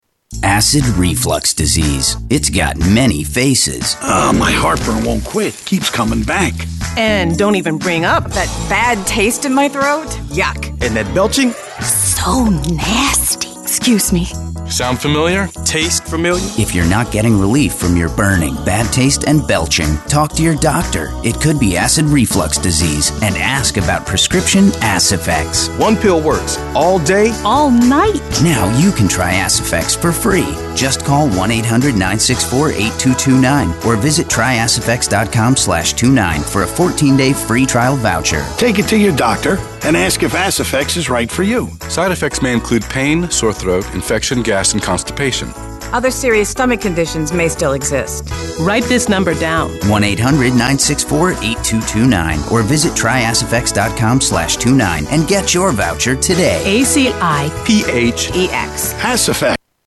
TV commerical